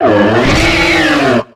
Cri de Blindépique dans Pokémon X et Y.